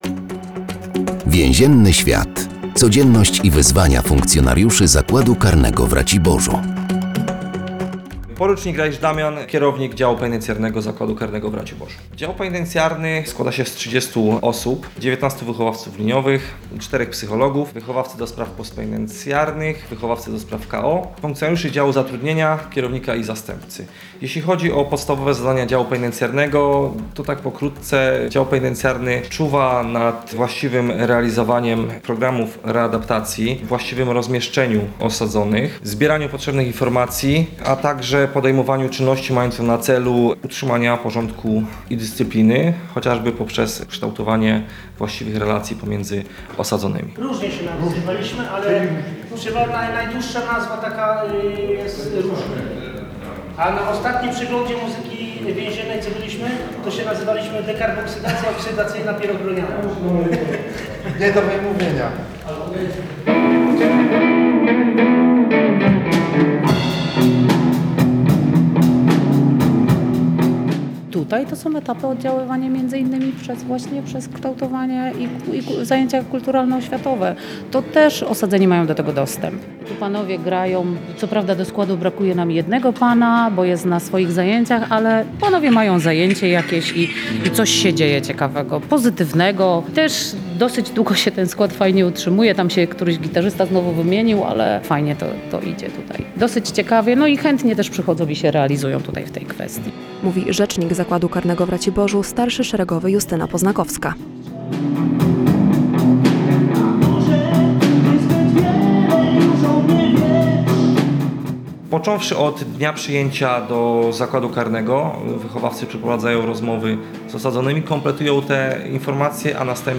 W każdym odcinku audycji “Więzienny świat” funkcjonariusze zakładu karnego w Raciborzu opowiadają o specyfice swojej pracy.